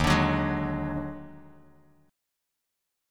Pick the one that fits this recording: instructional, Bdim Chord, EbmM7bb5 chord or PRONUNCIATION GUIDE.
EbmM7bb5 chord